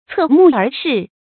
注音：ㄘㄜˋ ㄇㄨˋ ㄦˊ ㄕㄧˋ
側目而視的讀法